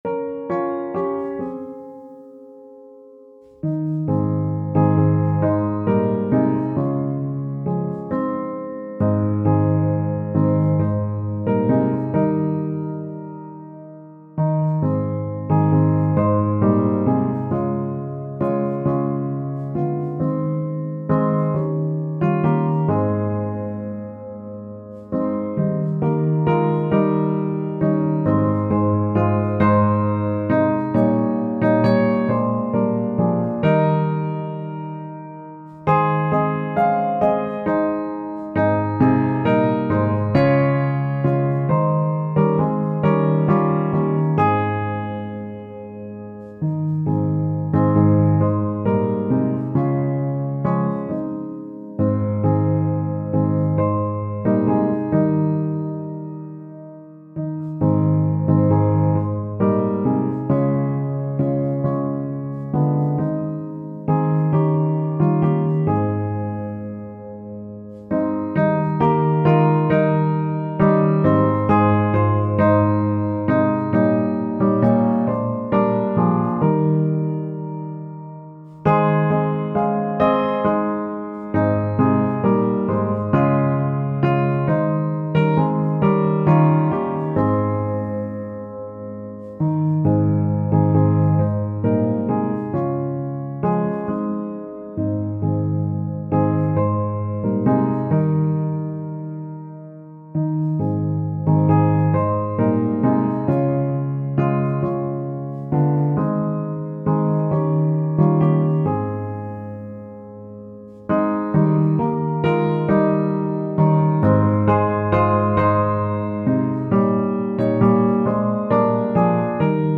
Tempo: Normaal